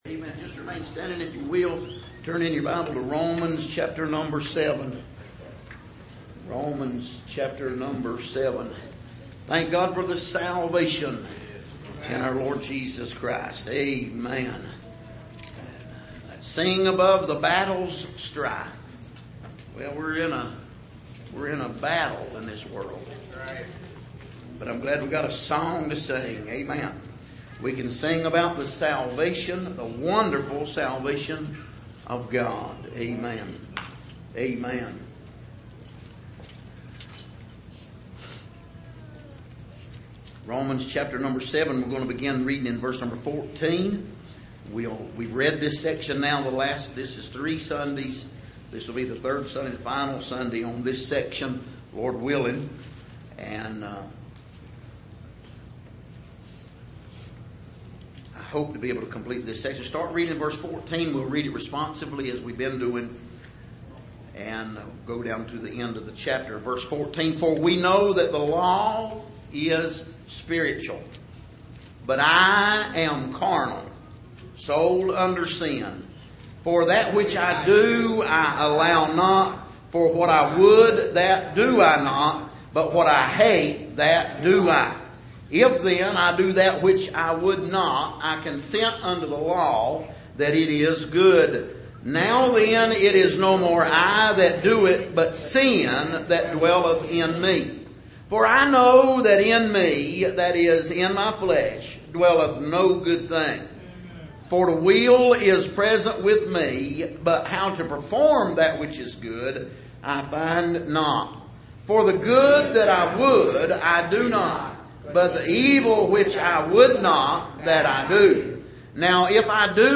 Passage: Romans 7:14-25 Service: Sunday Morning